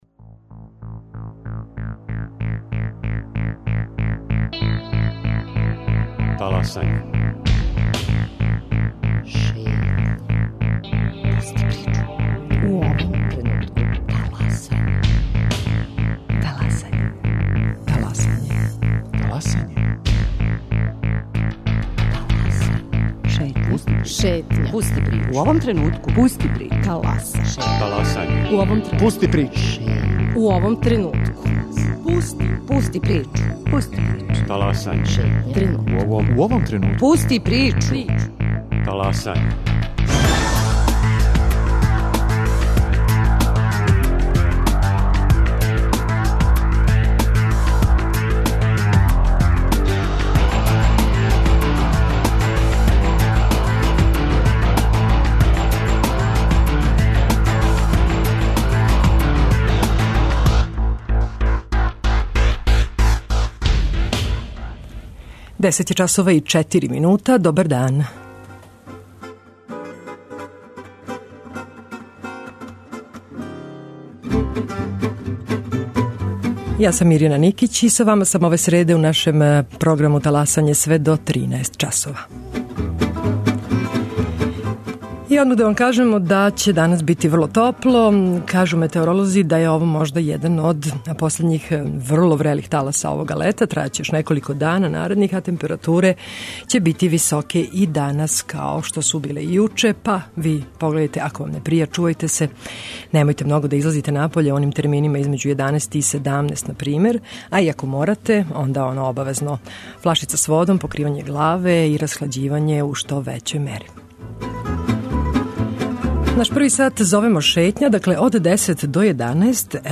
У Шетњи говоримо о броју пријава које су стигле и начину на који су разрешене. У репортажи из Косовске Митровице чућете приче грађана о томе како проводе ове летње месеце, како се одмарају, шта читају...